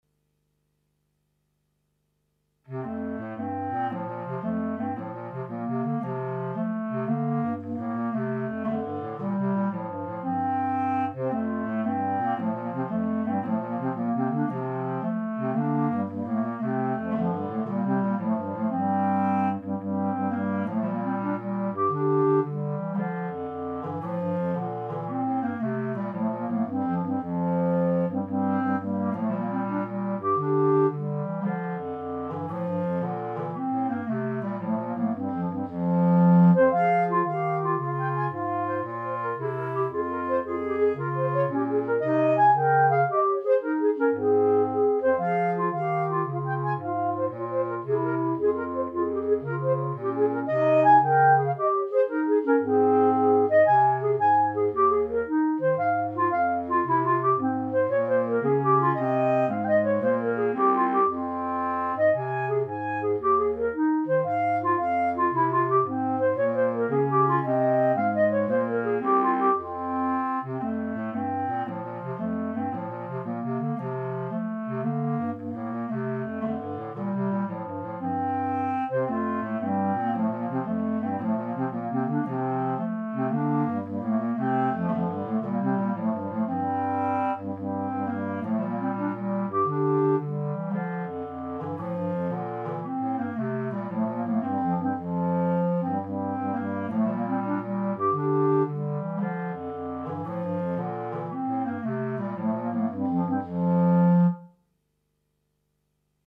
minus Clarinet 3